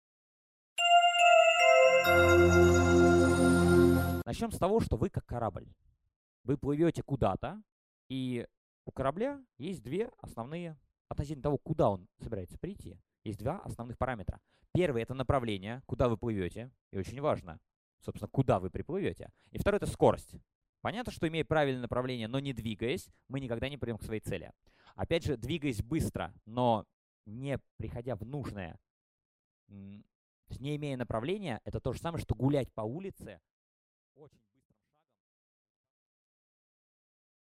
Аудиокнига Как образуются нейронные связи и как запоминать больше | Библиотека аудиокниг